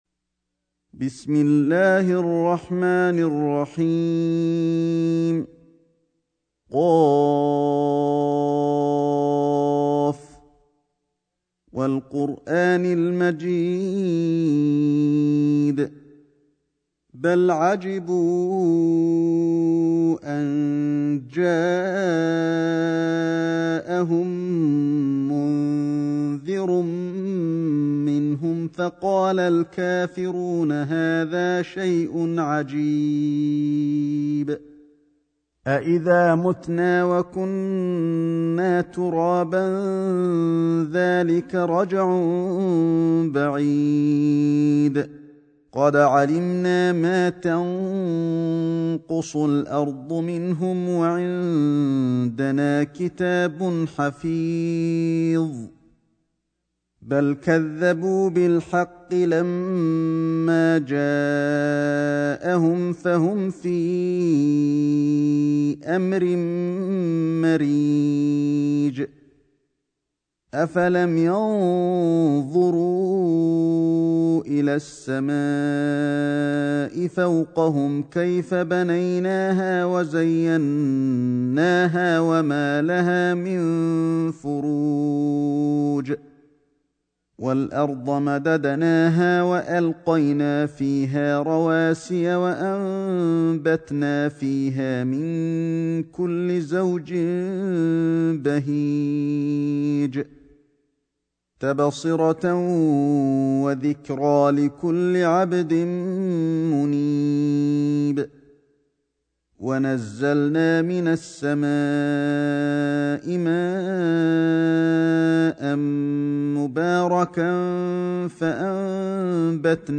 سورة ق > مصحف الشيخ علي الحذيفي ( رواية شعبة عن عاصم ) > المصحف - تلاوات الحرمين